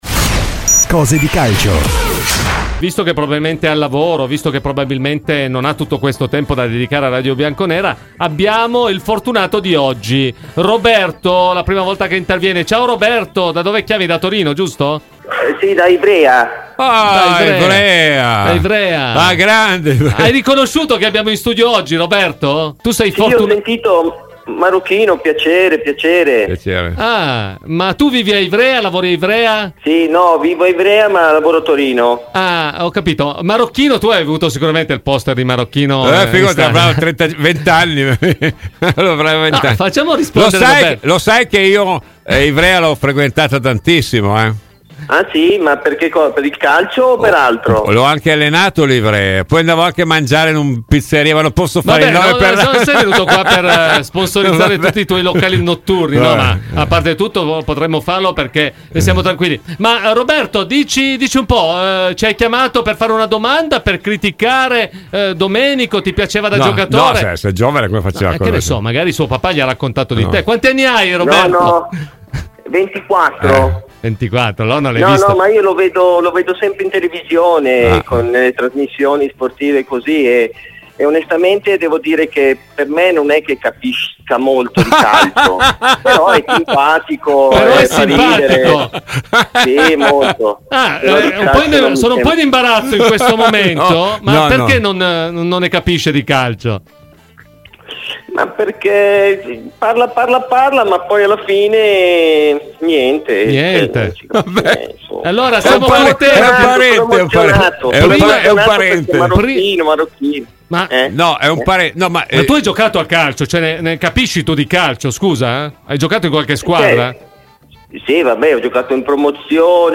Roberto Tavola (ex giocatore Juventus) ai microfoni di "Cose di calcio".